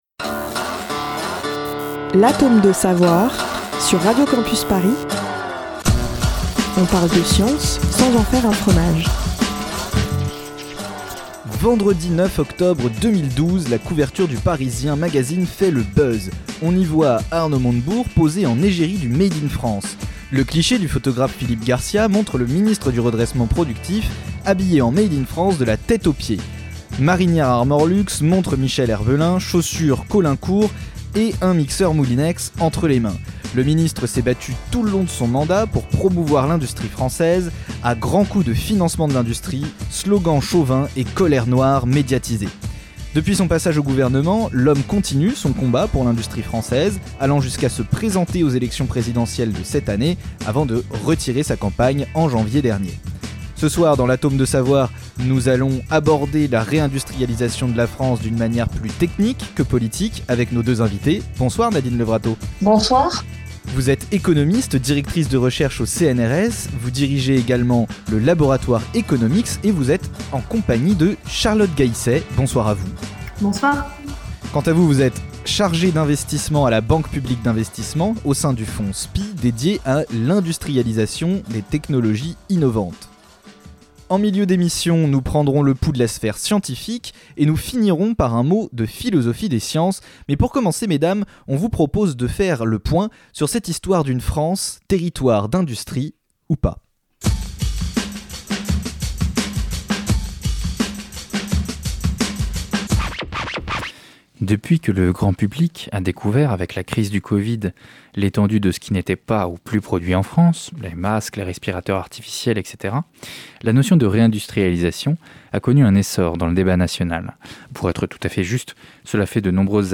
Nos deux invitées nous expliquent les stratégies que les entreprises mettent en place pour rester compétitives